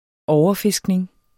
Udtale [ ˈɒwʌˌfesgneŋ ]